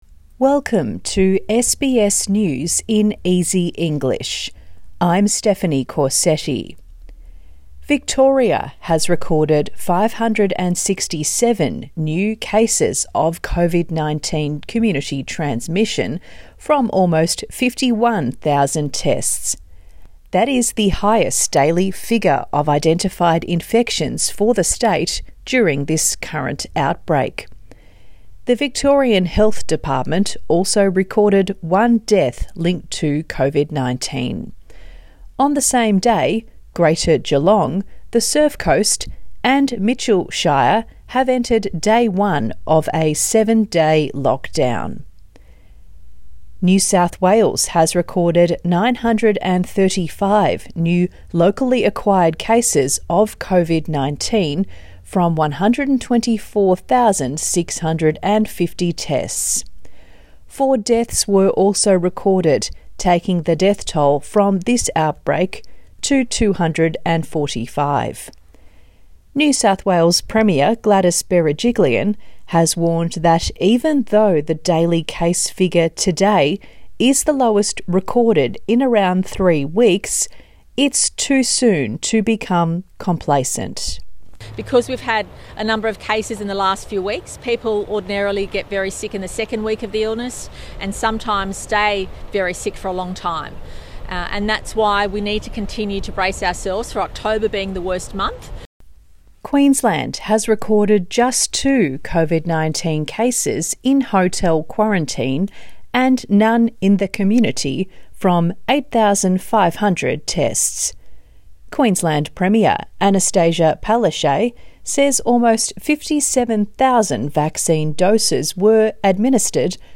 A daily five minute news wrap for English learners.